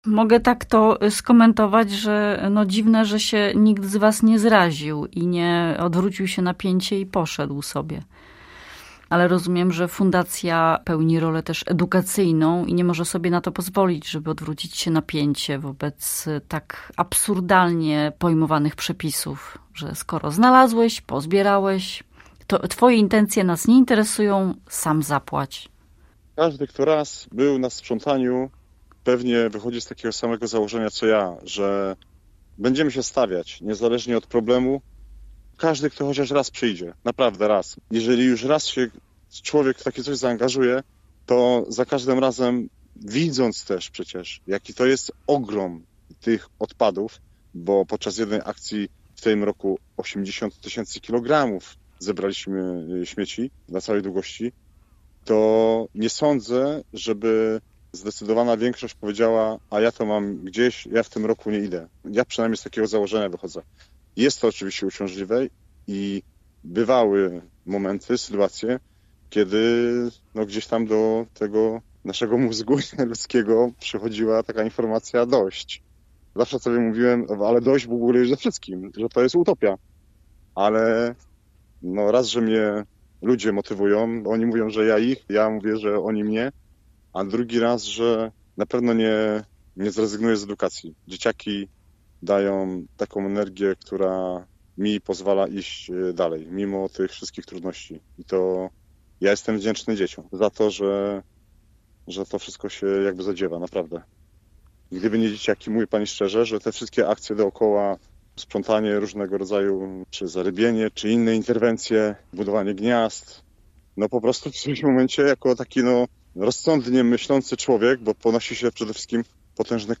Rozmowa z ichtiologiem i edukatorem